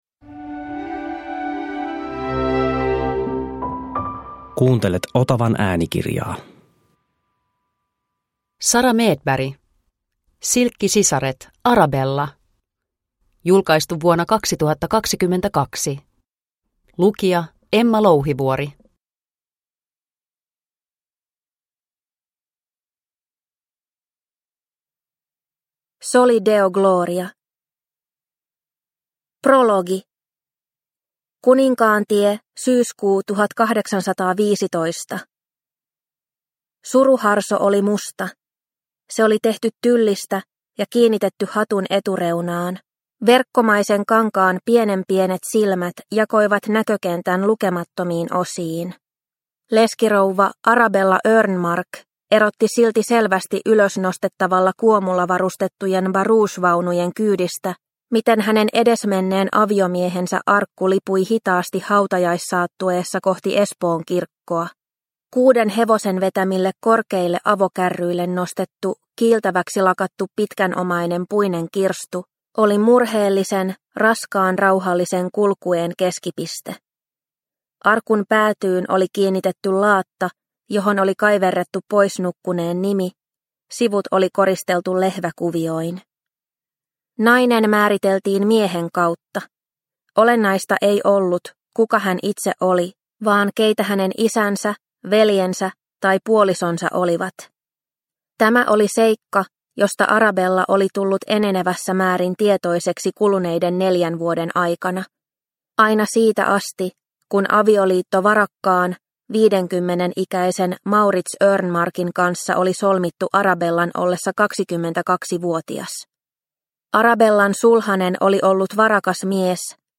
Silkkisisaret - Arabella – Ljudbok – Laddas ner